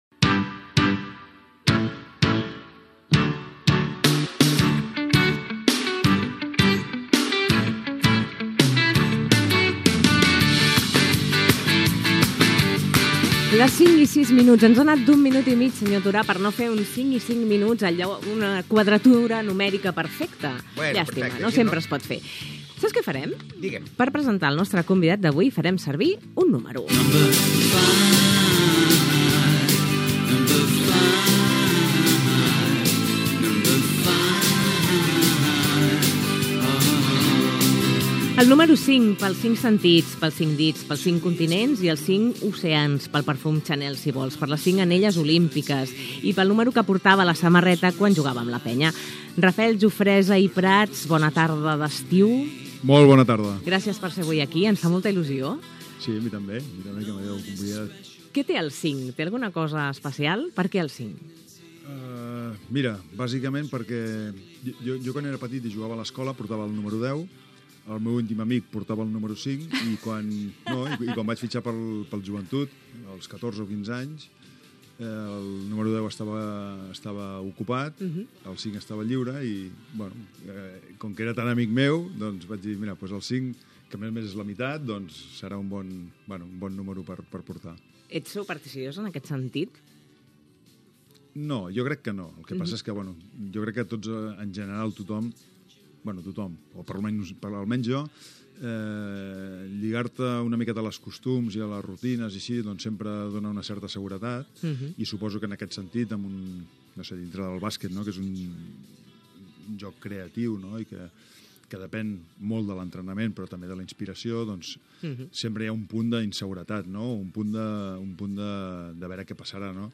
Hora, entrevista al jugador de bàsquet Rafael Jofresa: el número 5, la seva família, la popularitat del bàsquet
Entreteniment